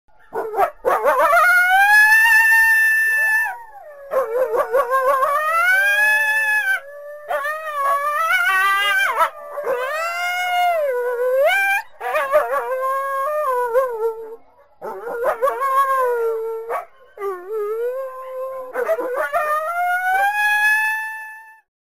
Coyote Sound Effect Download: Instant Soundboard Button
Animal Sounds Coyote1,211 views